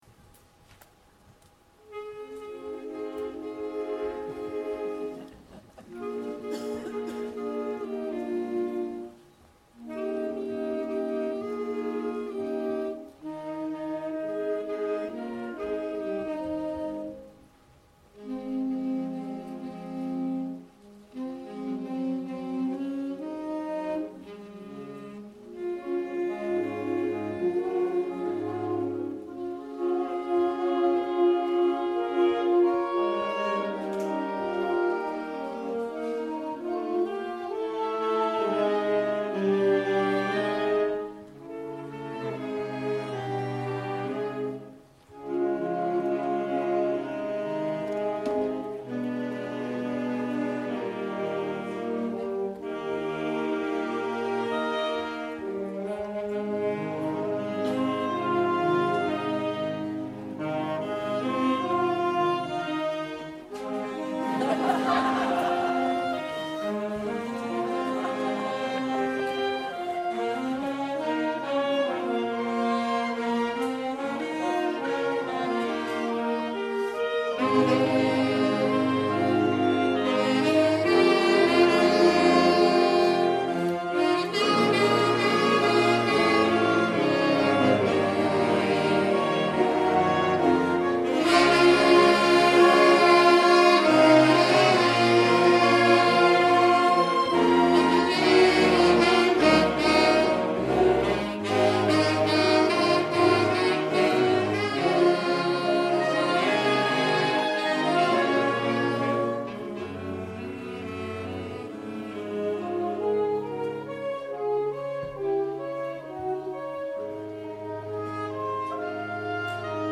Performed at the Autumn Concert, November 2014 at the Broxbourne Civic Hall.